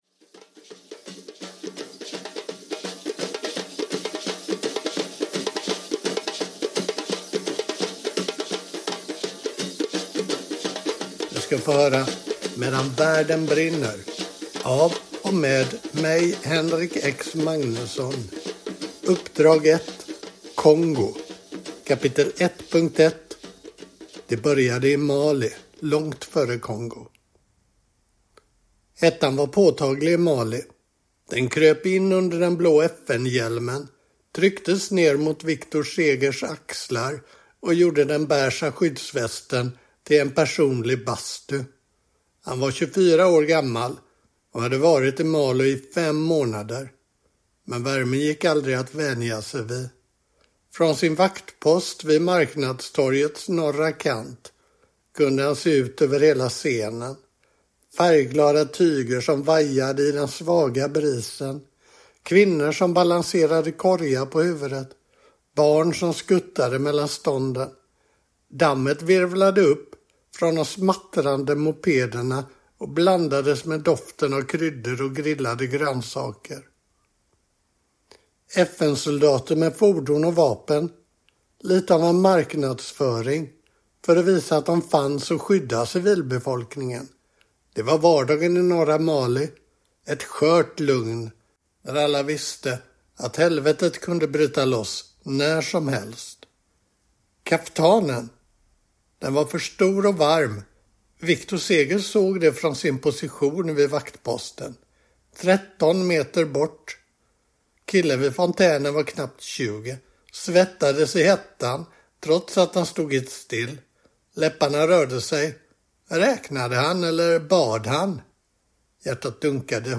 Medan världen brinner – Ljudbok